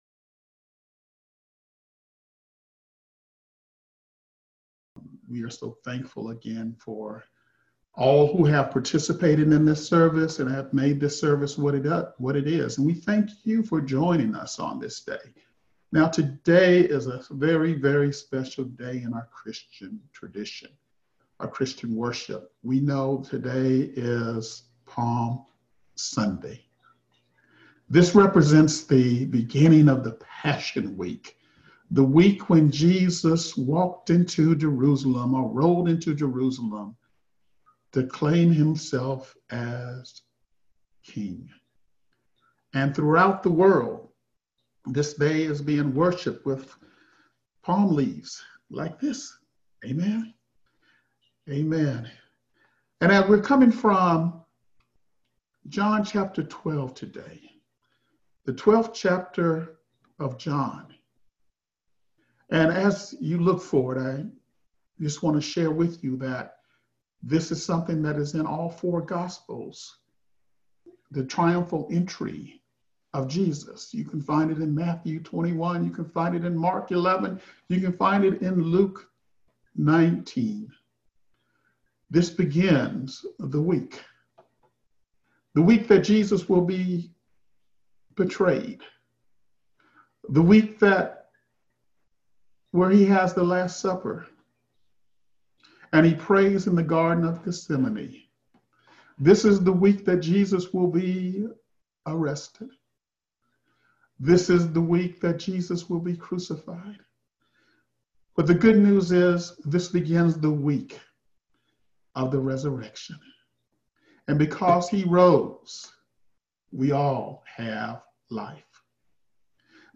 Enjoy this Psalm Sunday message from John 12